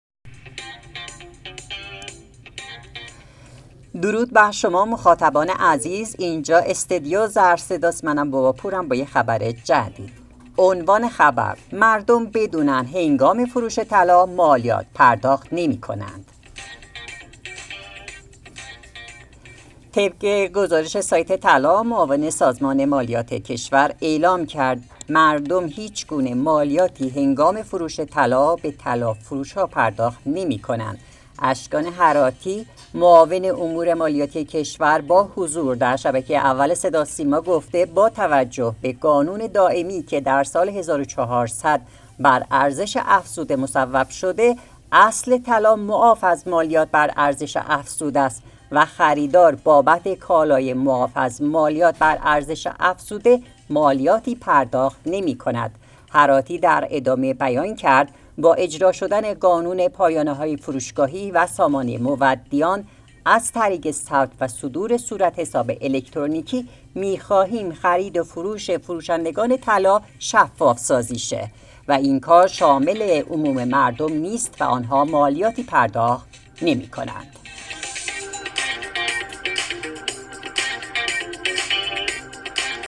اشکان هراتی معاون امور مالیاتی کشور با حضور در شبکه اول صدا و سیما گفت: